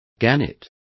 Complete with pronunciation of the translation of gannet.